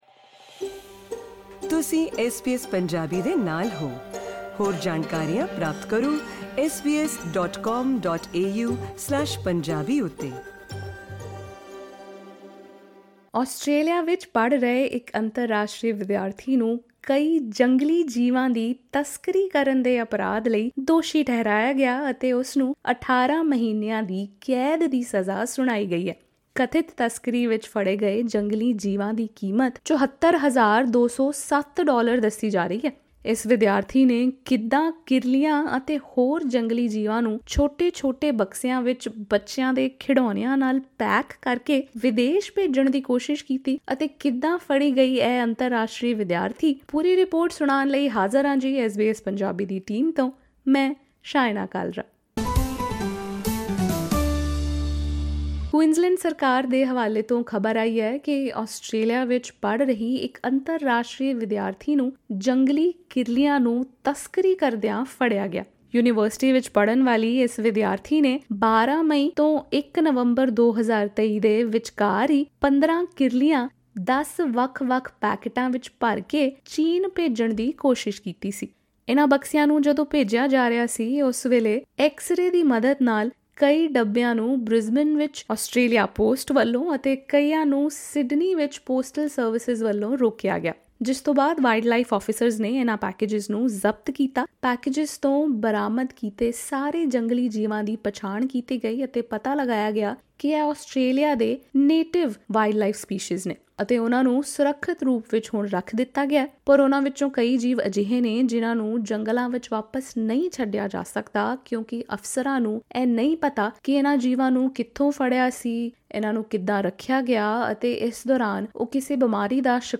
ਜਾਨਣ ਲਈ ਸੁਣੋ ਐਸ ਬੀ ਐਸ ਪੰਜਾਬੀ ਦੀ ਇਹ ਰਿਪੋਟ...